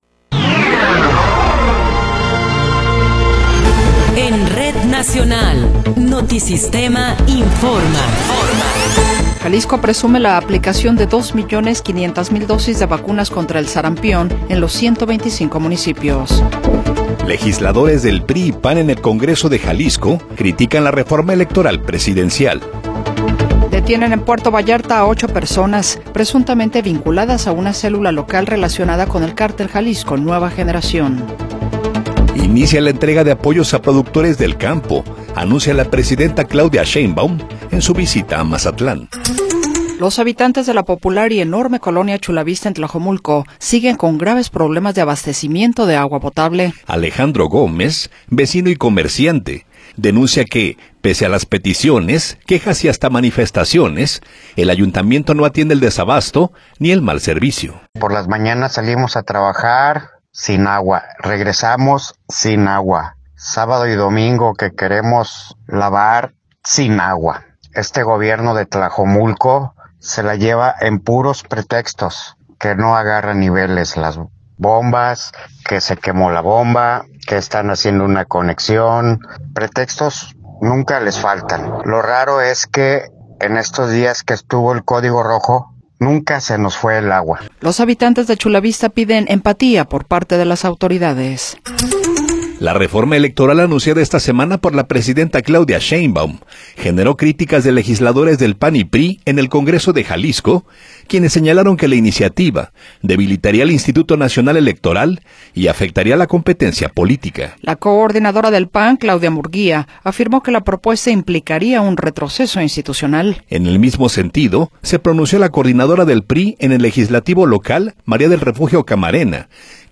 Noticiero 14 hrs. – 27 de Febrero de 2026
Resumen informativo Notisistema, la mejor y más completa información cada hora en la hora.